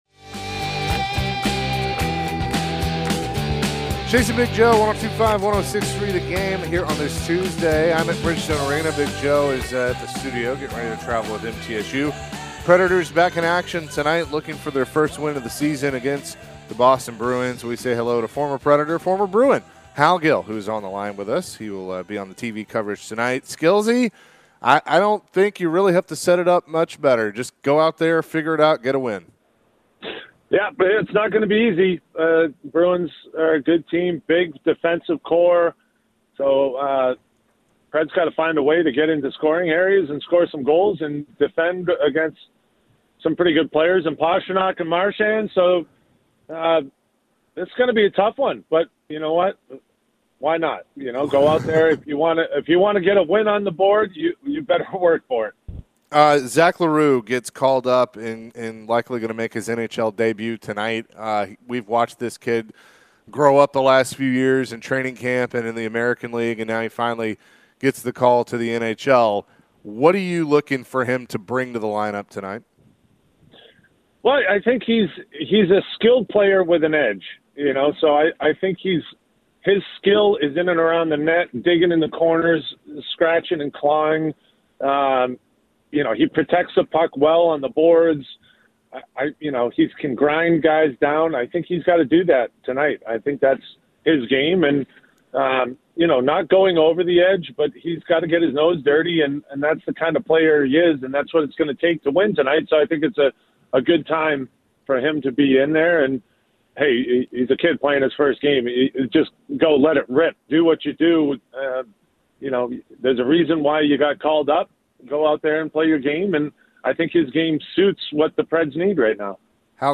Predators radio and TV analyst Hal Gill joined the show to discuss the Predators' slow start to the season. What do the Preds need to do to get their first win of the season?